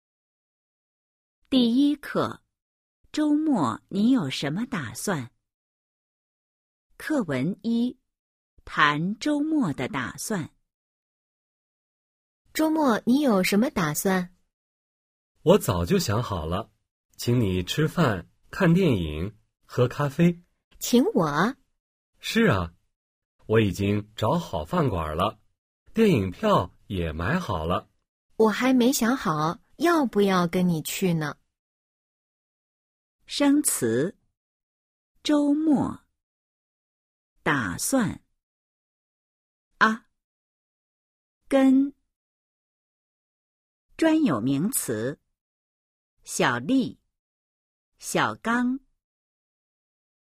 Bài hội thoại 1: 🔊 谈周末的打算 Nói về kế hoạch cuối tuần  💿 01-01